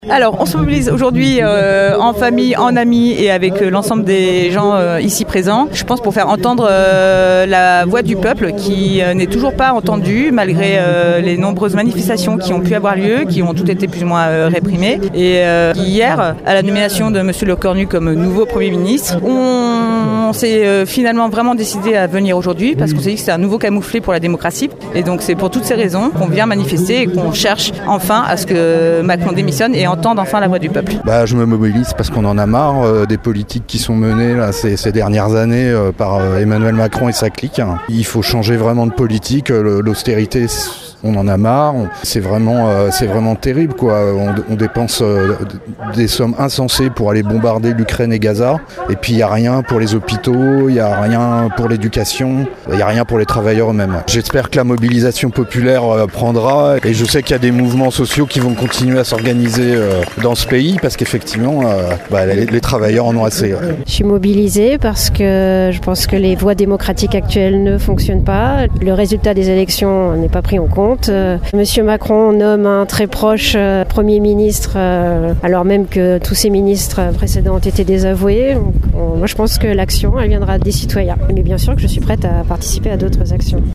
Manifestation hier matin place Colbert à Rochefort.